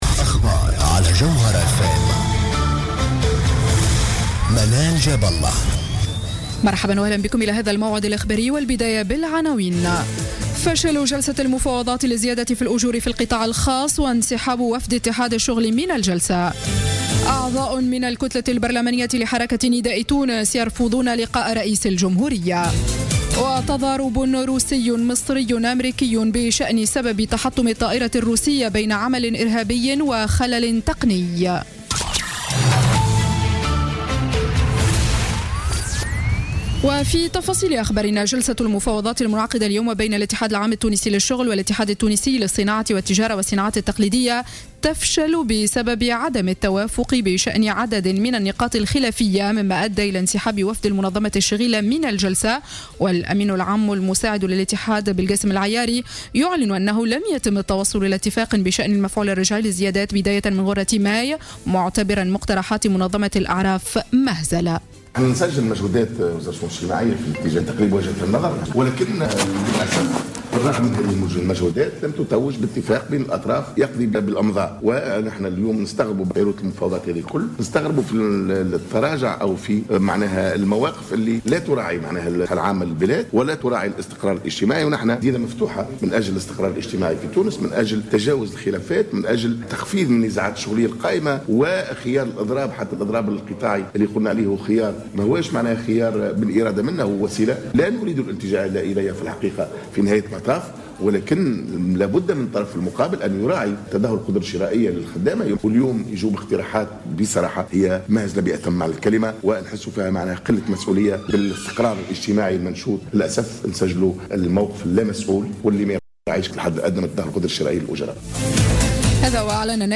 نشرة أخبار السابعة مساء ليوم الاثنين 2 نوفمبر 2015